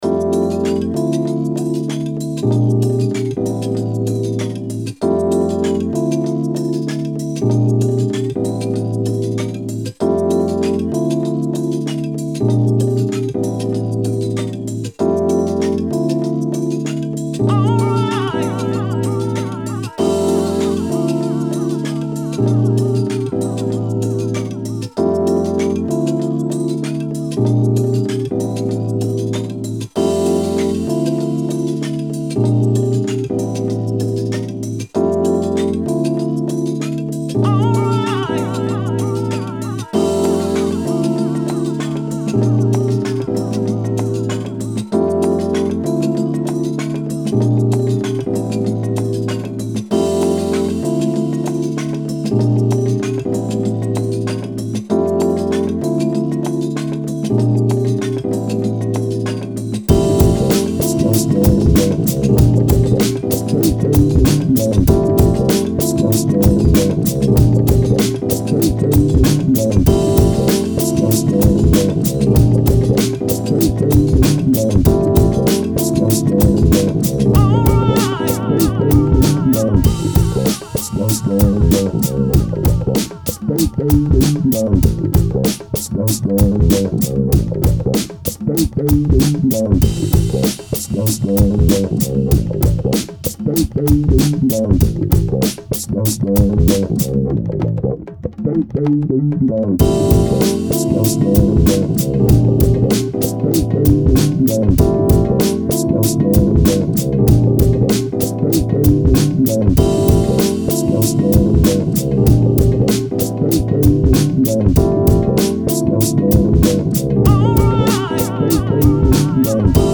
Chill